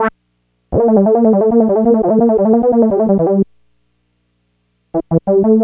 Synth 18.wav